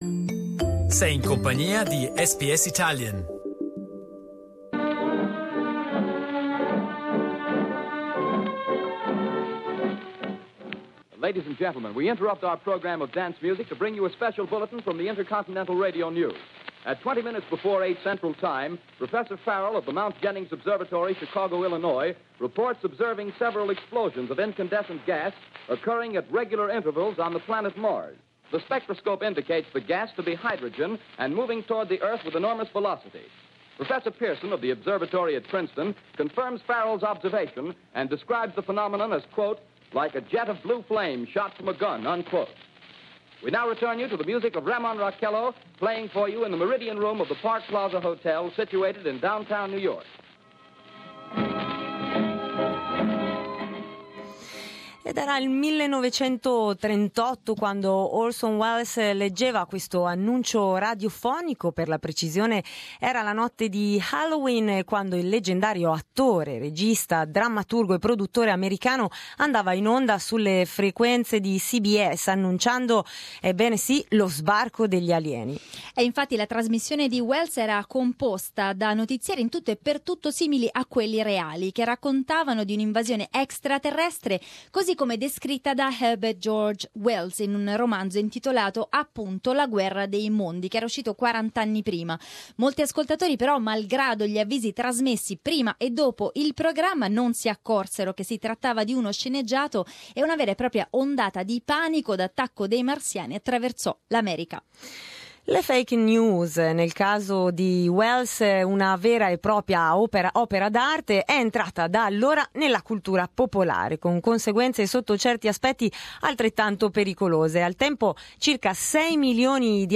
Il nostro ospite è Luciano Floridi, Professore di Filosofia ed Etica dell'informazione alla University of Oxford.